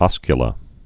(ŏskyə-lə)